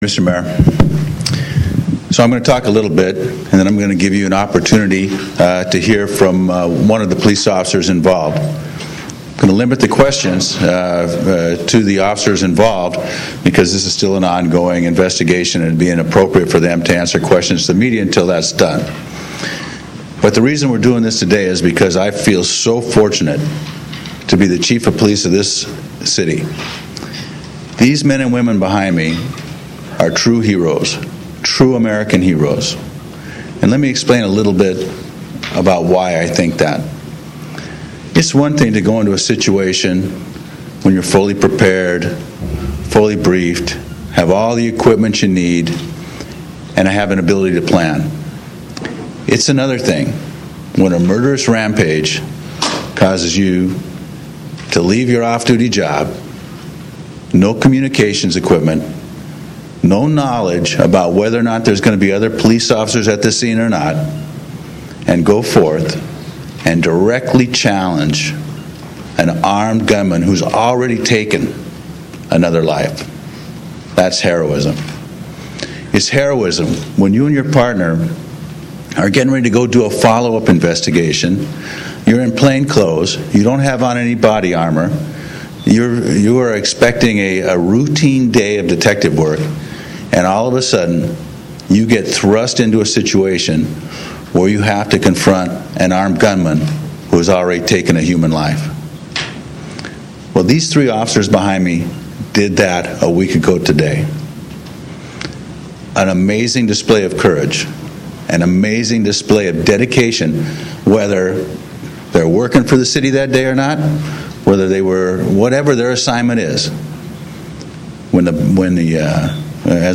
Los Angeles:  At a news conference today, Mayor Antonio Villaraigosa, Chief Beck, and Los Angeles City Council President Eric Garcetti honored LAPD officers who were responsible for stopping a gunman firing multiple shots at Sunset Boulevard and Vine Street on Dec. 9 of last week.